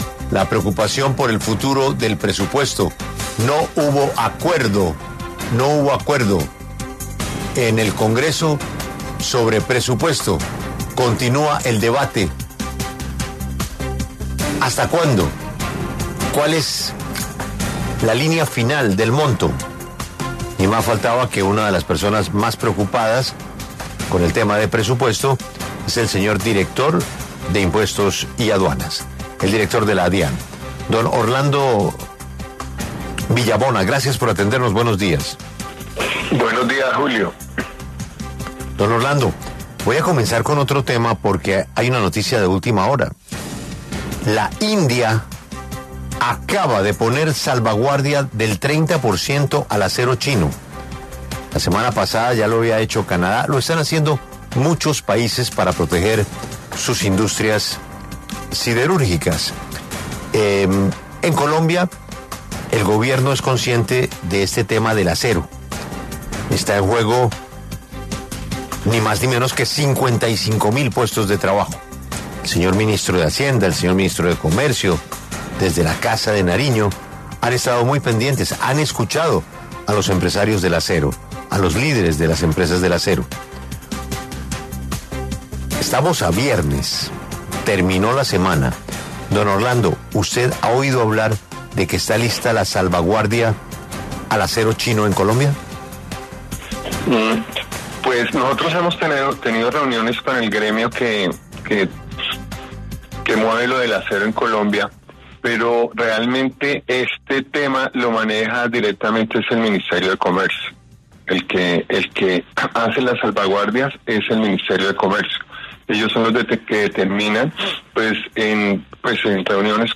Orlando Villabona, director de la DIAN, se refirió en La W a la reforma tributaria que el Gobierno espera sacar adelante para obtener $12 billones de pesos que financien el Presupuesto General de la Nación.